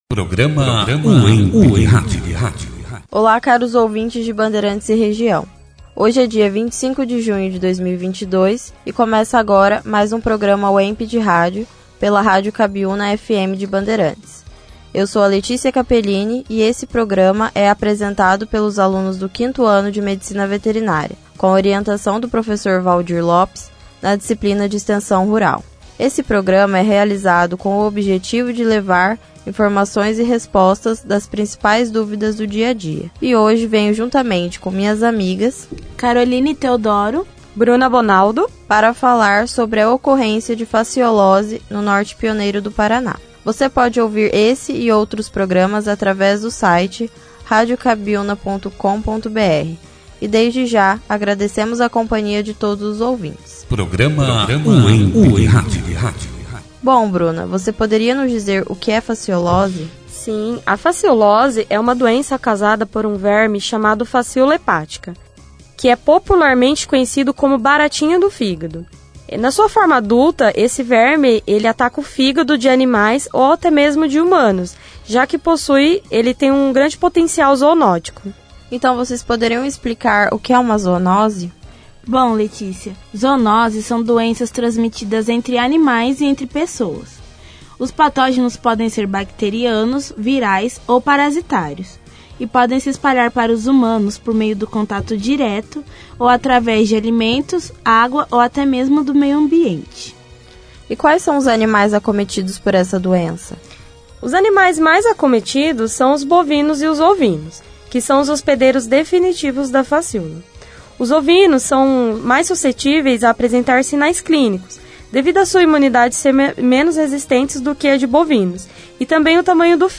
Produzido e apresentado pelos alunos, Acadêmicos do 5º ano do curso de Medicina Veterinária.